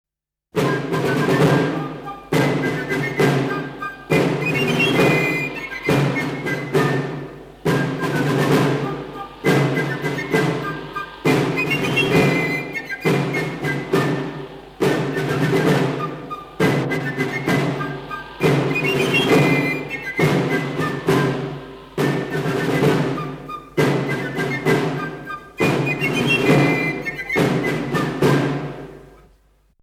Franse signalen - Aux Drapeaux.mp3